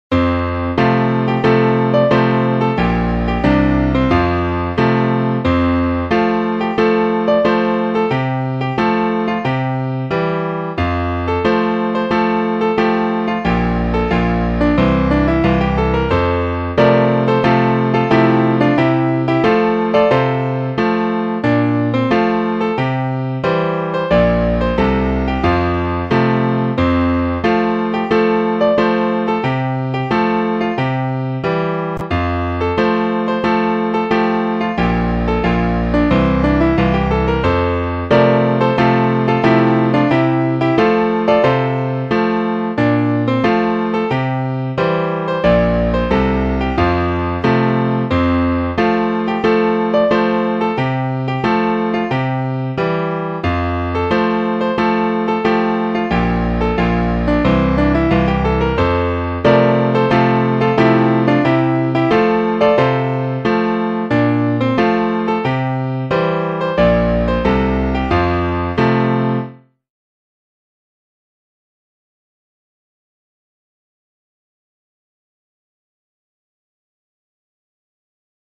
○校歌